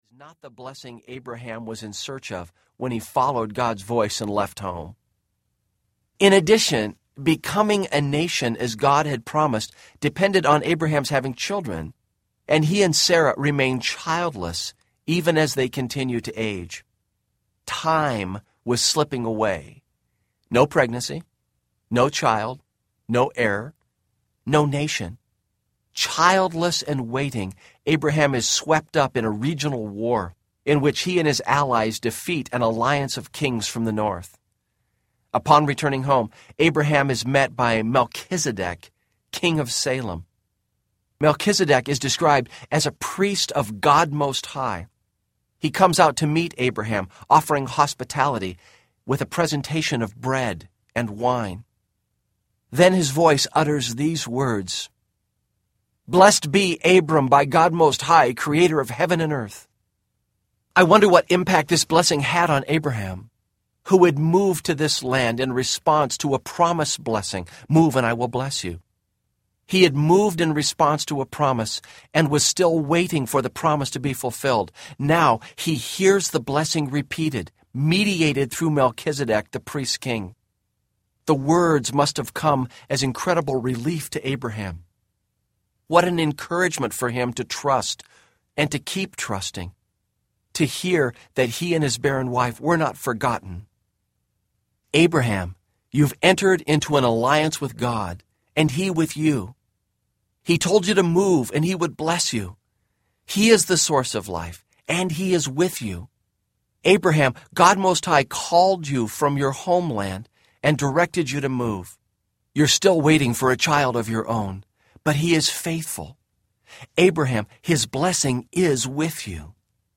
The Land Between Audiobook